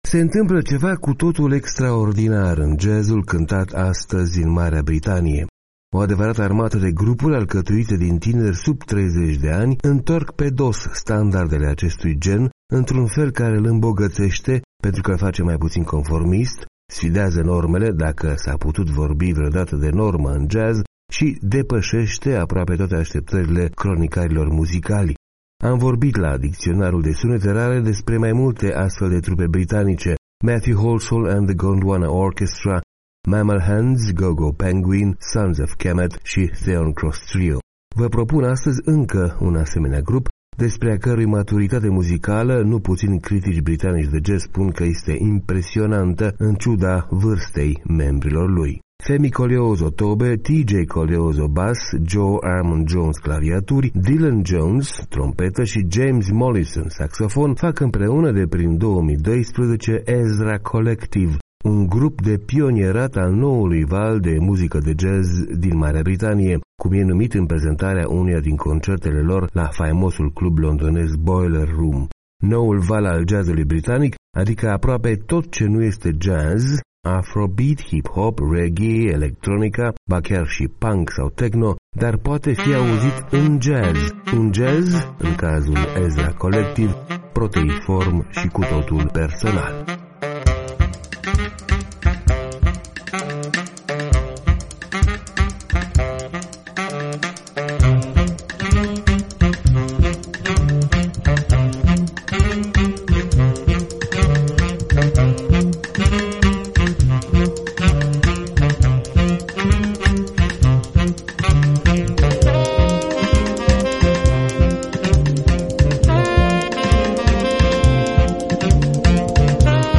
Noul val al jazz-ului britanic, adică aproape tot ce nu este jazz (afro-beat, hip-hop, reggae, electronica, ba chiar și punk sau techno), dar poate fi auzit în... jazz.